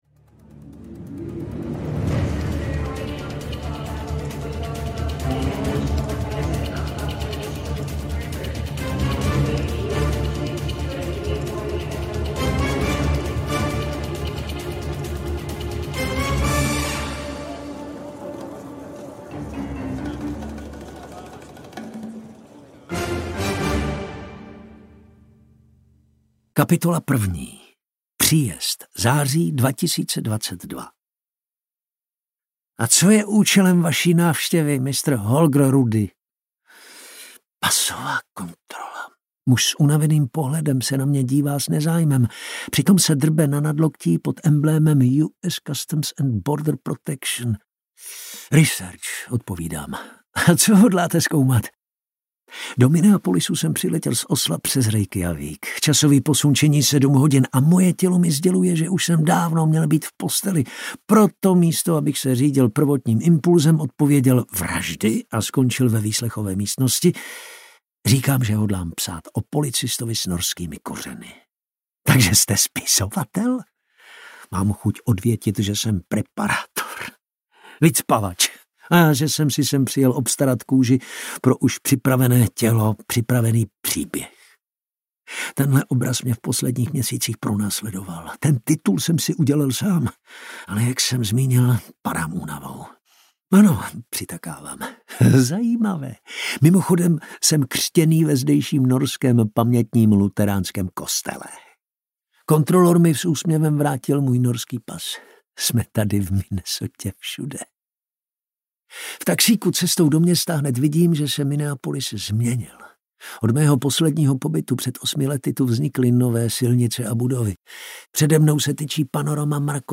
Osamělý vlk audiokniha
Ukázka z knihy